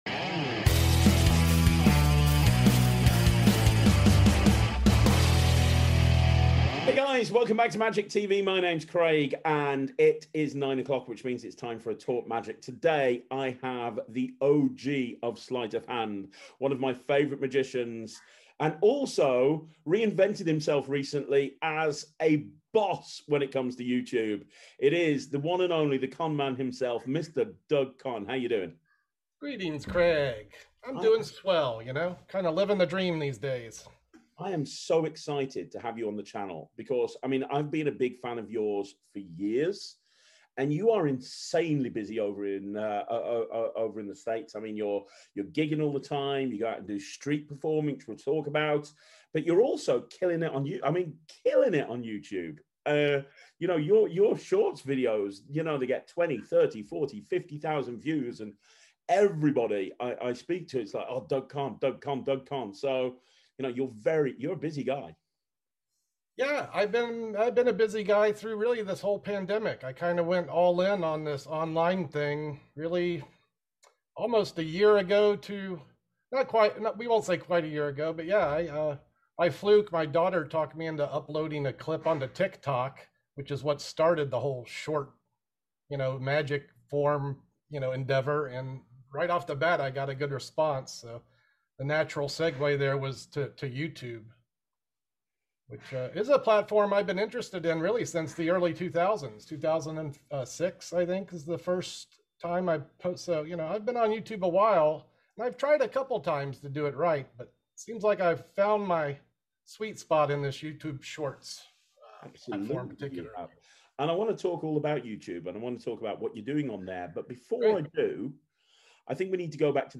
This is an interview you really don't want to miss.